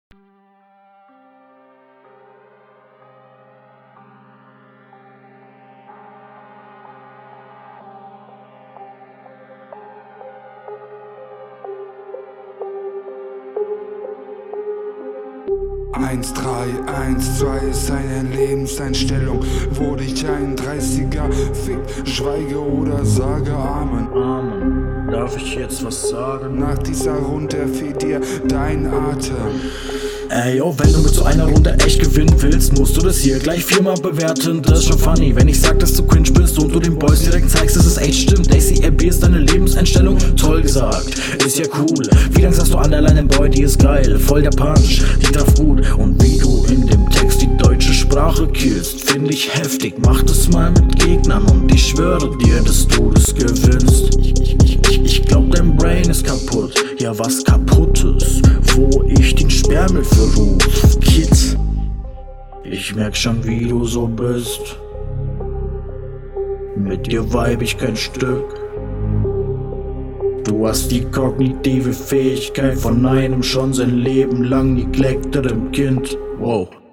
Deutlich besser Flow, aber der Text ist schon wieder fast ohne einem Reim.